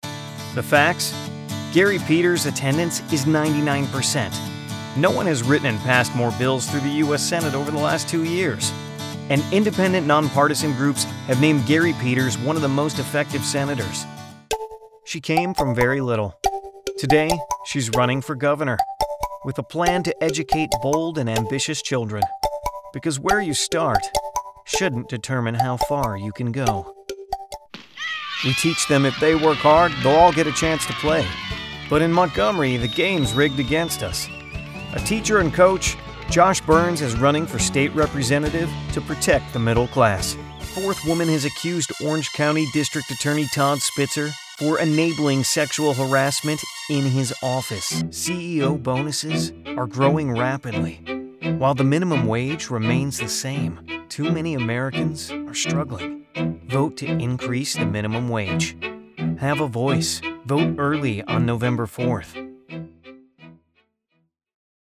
Voice Over Demos (Downloadable)